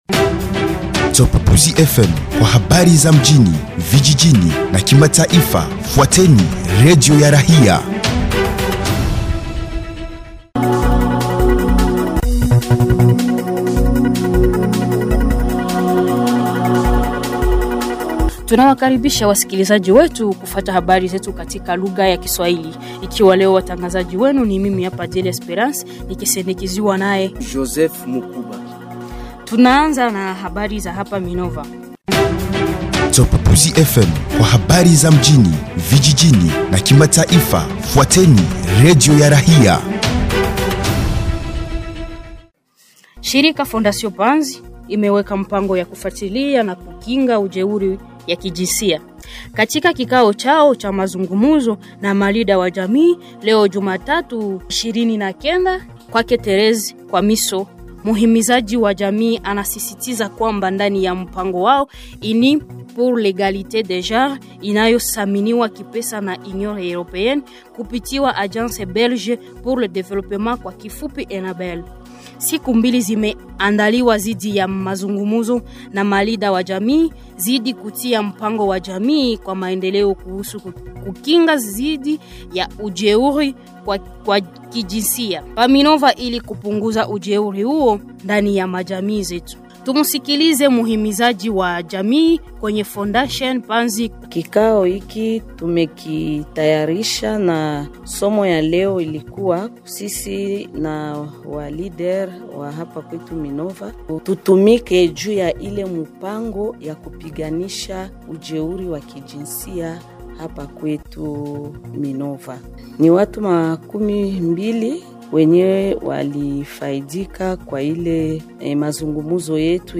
Journal swahili 29 septembre 2025